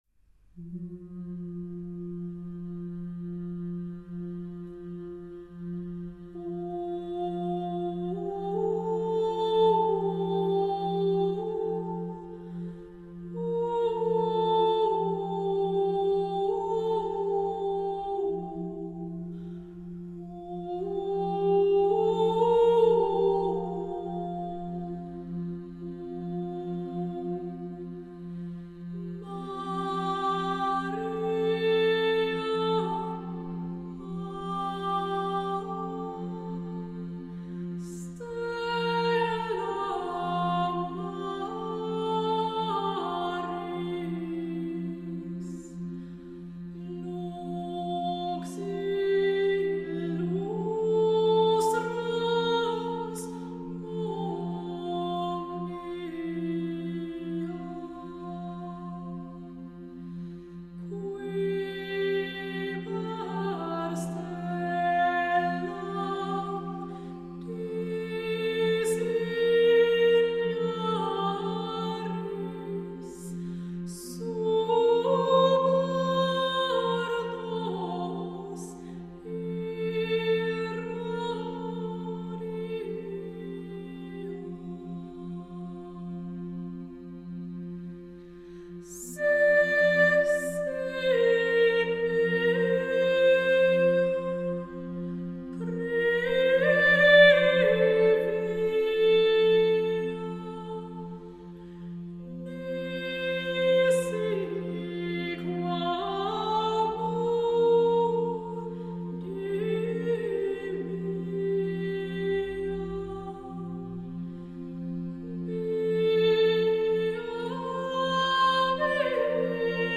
ensemble vocale